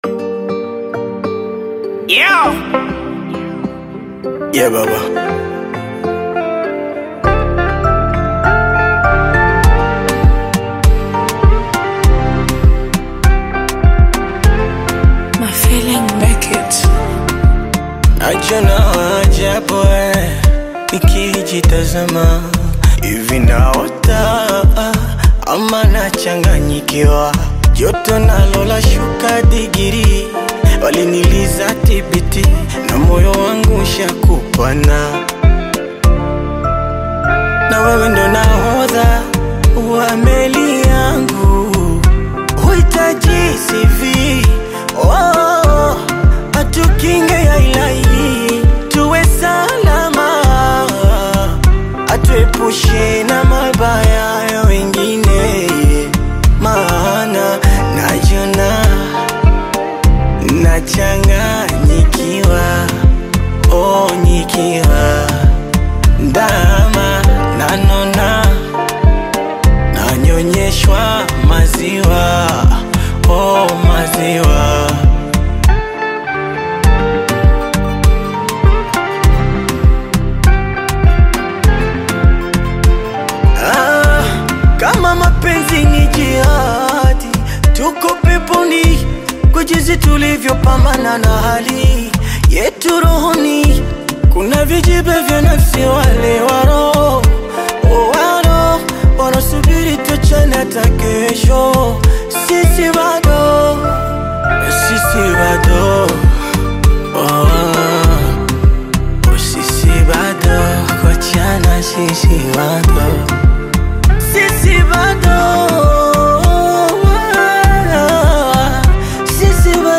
Tanzanian Bongo Flava artist, singer, and songwriter
Bongo Flava